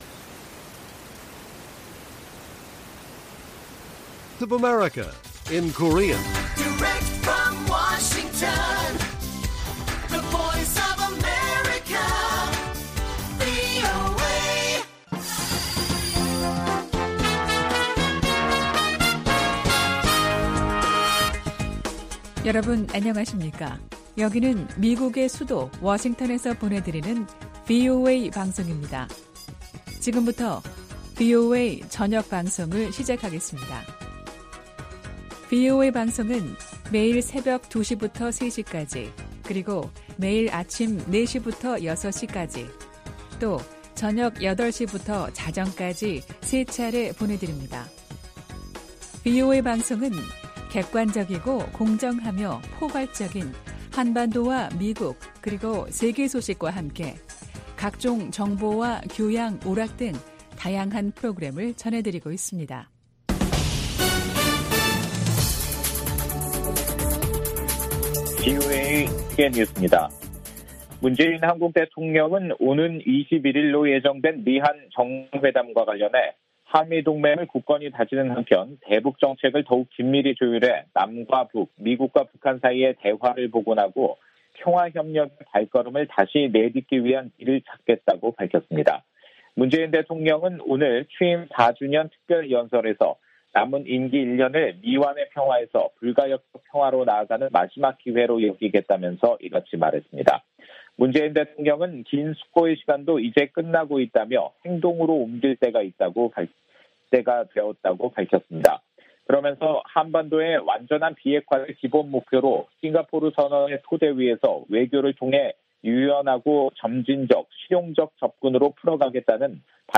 VOA 한국어 간판 뉴스 프로그램 '뉴스 투데이' 1부 방송입니다.